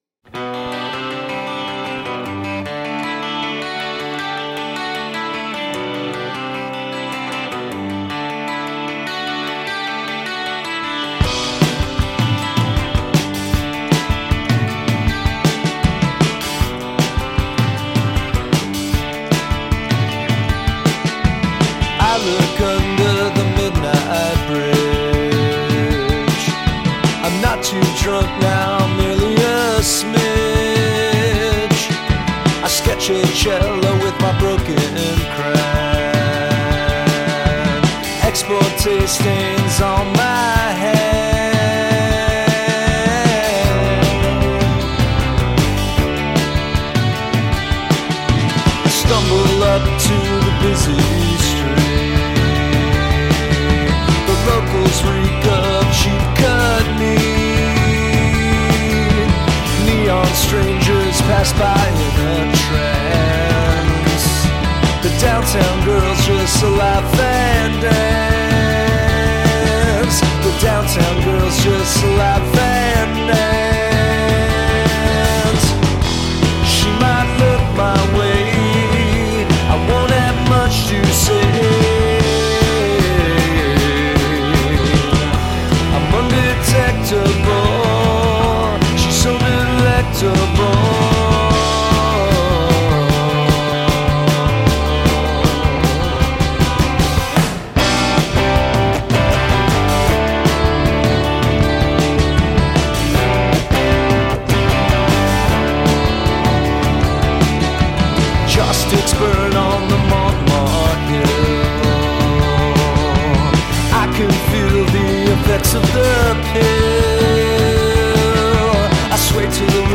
Звон гитары фирмы Rickenbacker
кантри поп